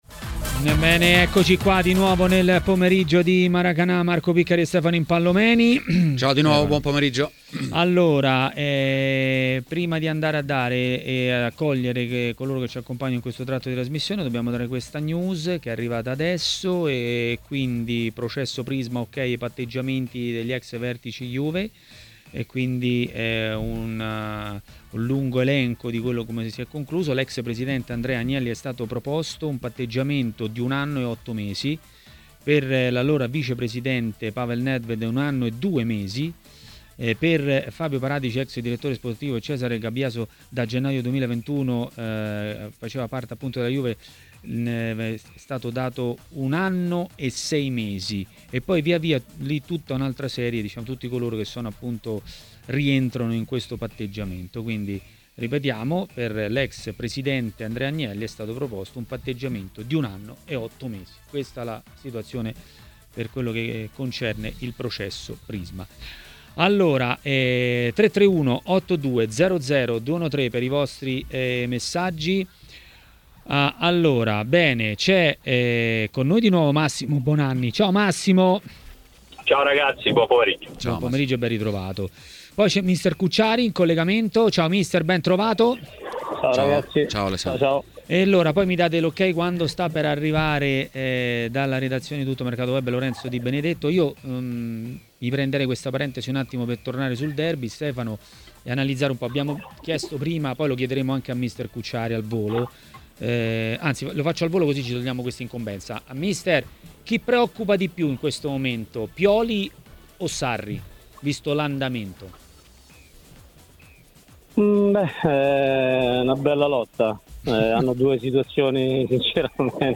è intervenuto a TMW Radio, durante Maracanà.